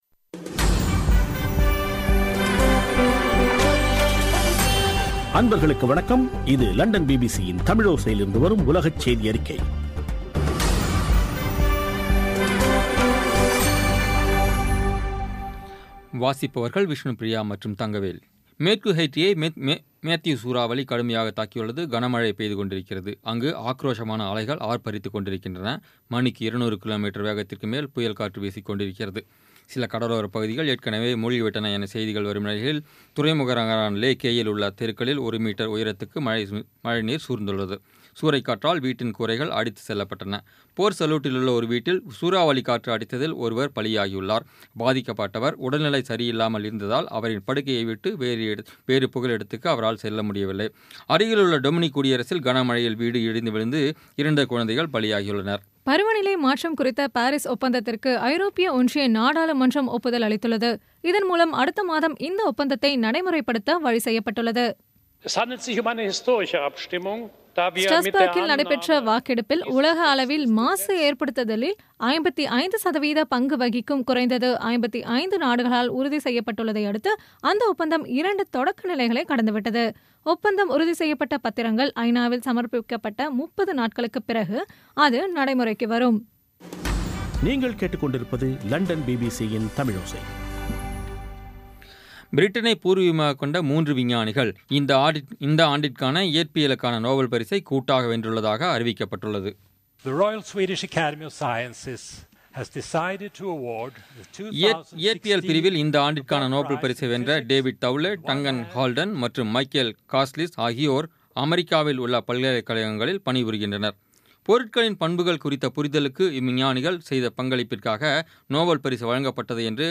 இன்றைய (அக்டோபர் 4ம் தேதி ) பிபிசி தமிழோசை செய்தியறிக்கை